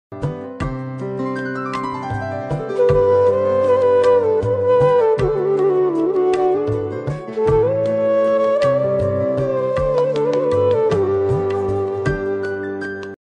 • Category: Old Bollywood Instrumental Ringtone
• Soft and relaxing instrumental music